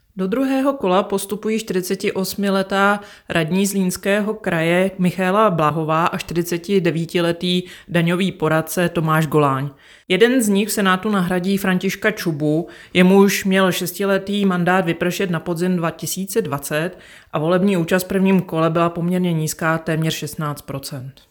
Vyjádření Evy Krumpové, místopředsedkyně ČSÚ , soubor ve formátu MP3, 834.74 kB